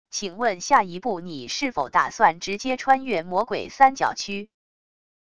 请问下一步你是否打算直接穿越魔鬼三角区wav音频生成系统WAV Audio Player